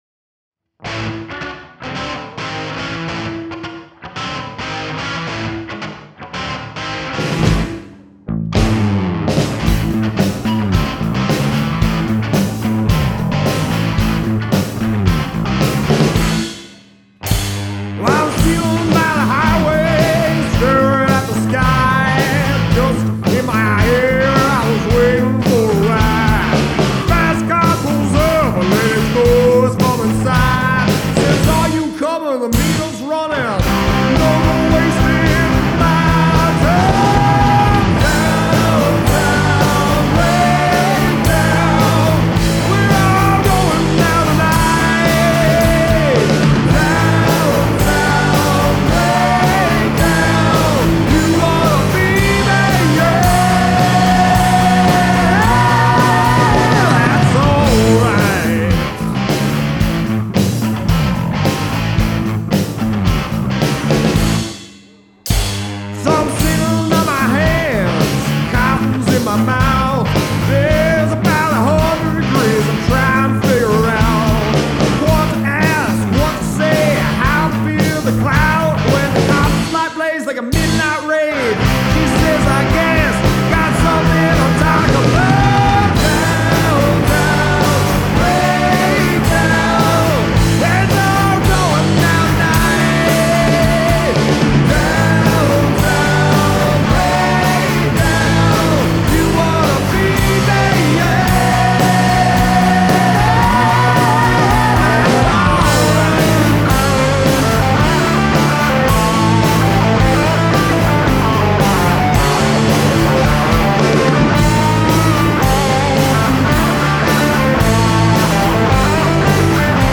Swing/Jazz/Rock
rock music song swing jazz